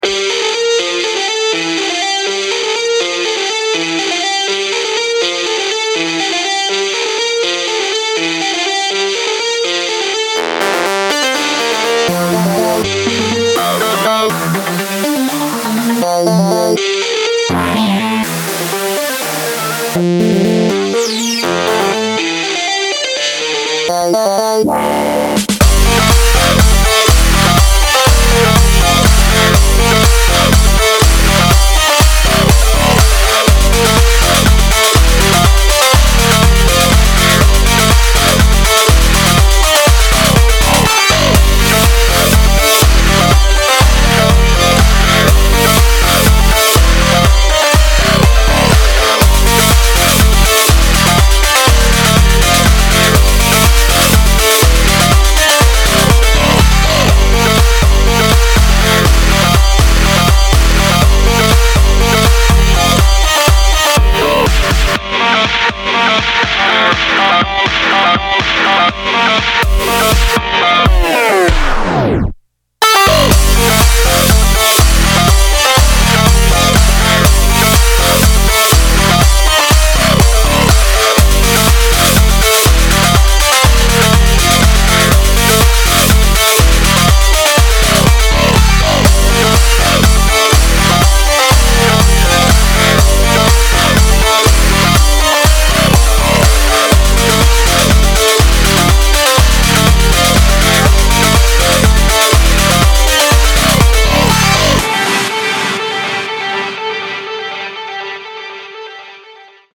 BPM122
Audio QualityPerfect (Low Quality)